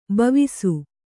♪ bavisu